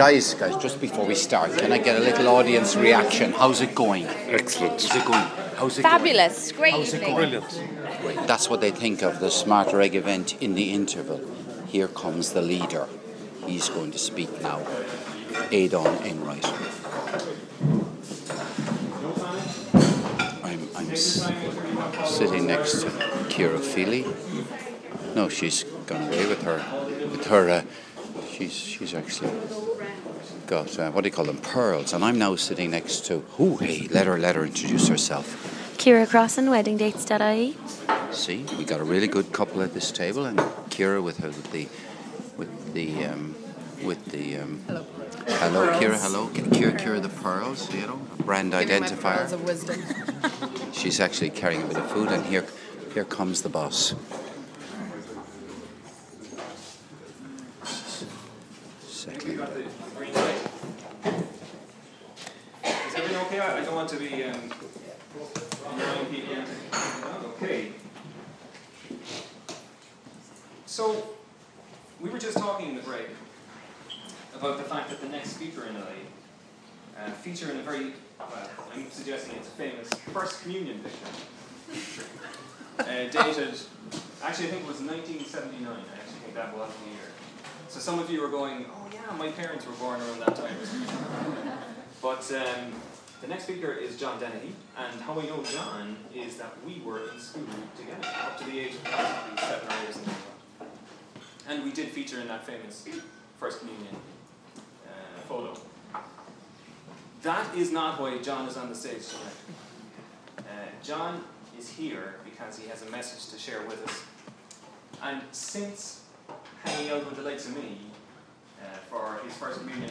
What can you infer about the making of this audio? You hear about the need to get the message out that there are unfilled jobs in Ireland A raw live recording